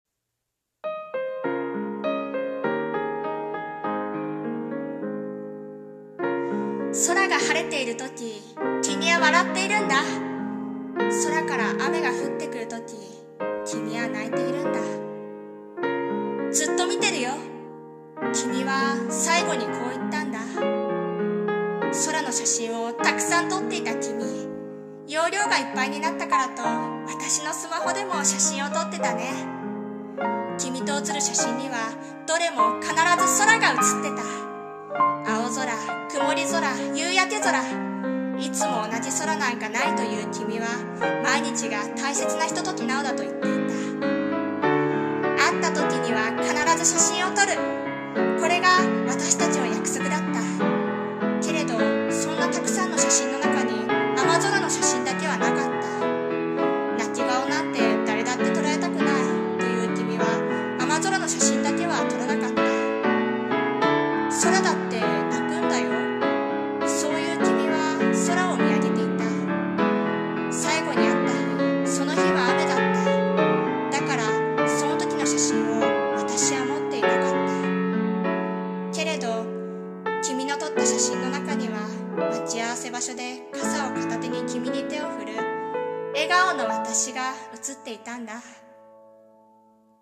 さんの投稿した曲一覧 を表示 【朗読台本】雨空写真